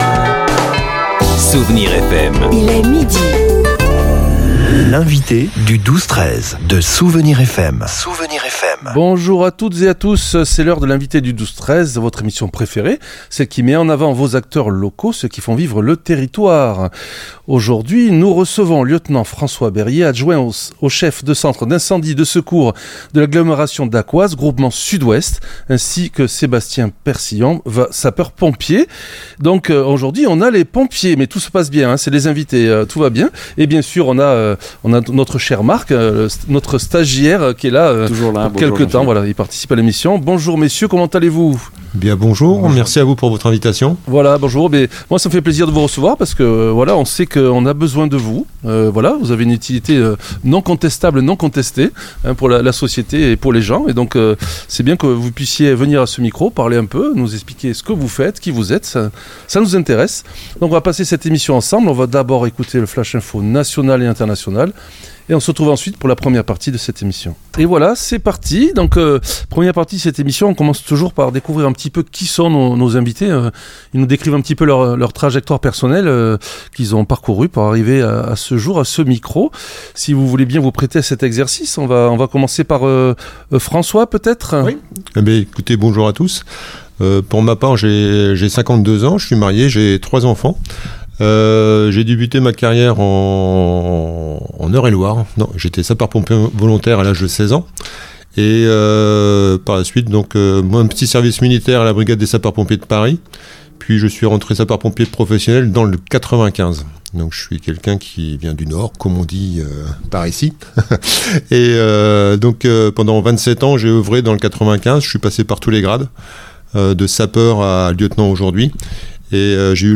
Avec près de 35 000 interventions de secours à personne par an (soit plus de 88 % de leur activité), les sapeurs-pompiers sont le premier rempart de notre sécurité quotidienne. L'entretien a permis de clarifier les différents visages de cet engagement : des professionnels de la fonction publique territoriale aux volontaires, sans oublier le Service de Santé et de Secours Médical (SSSM), essentiel pour la prise en charge des victimes. L'émission a également exploré la modernité des moyens déployés dans les Landes.